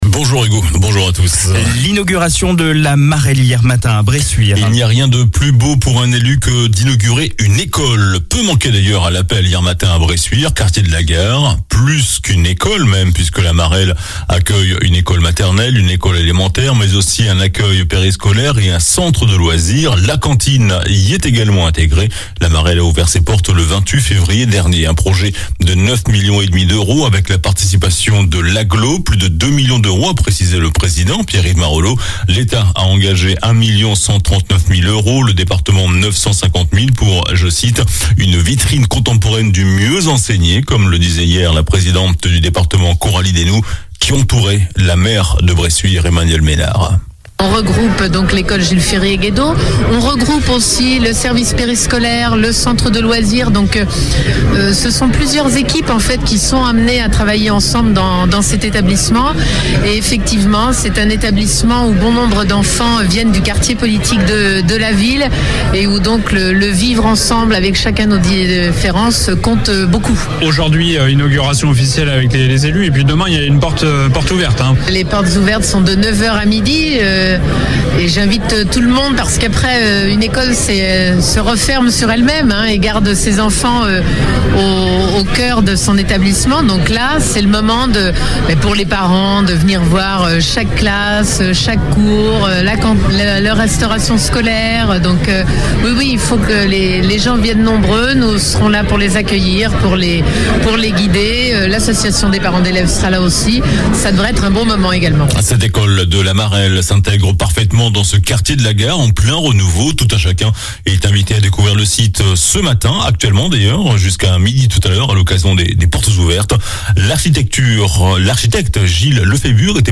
JOURNAL DU SAMEDI 21 MAI